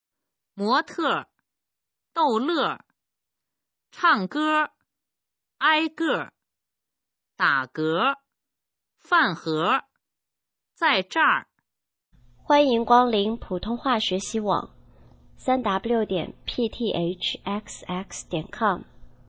普通话水平测试用儿化词语表示范读音第16部分